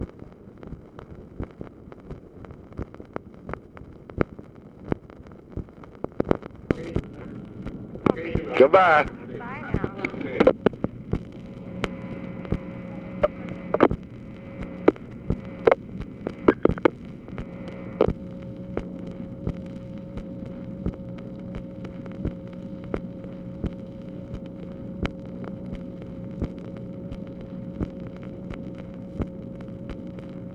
LBJ AND UNIDENTIFIED FEMALE SAYING "GOODBYE" ARE ONLY PORTIONS OF CONVERSATION THAT WERE RECORDED
Conversation with UNIDENTIFIED FEMALE, February 15, 1965
Secret White House Tapes